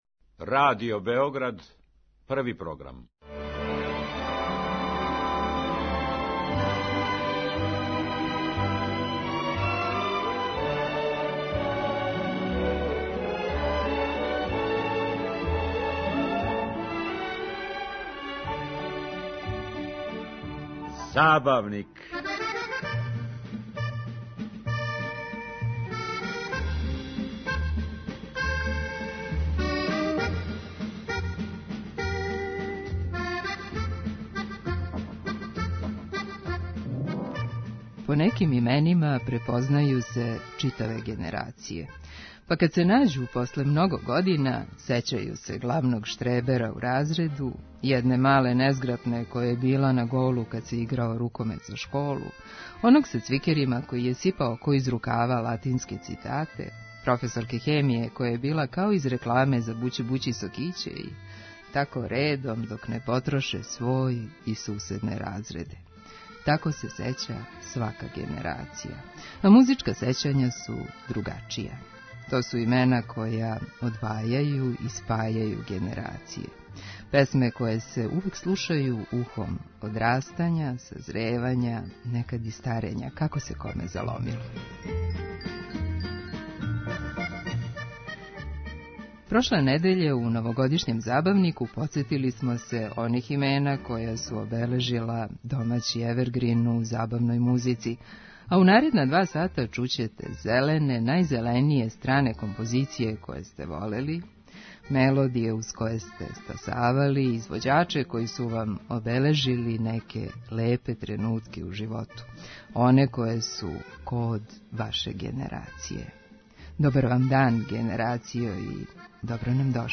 Прошле недеље, у новогодишњем 'Забавнику', подсетили смо се оних имена која су обележила домаћи евергрин у забавној музици, а у наредна два сата чућете зелене, најзеленије стране композиције које сте волели, мелодије уз које сте стасавали, причице о извођачима који су вам обележили неке лепе тренутке у животу.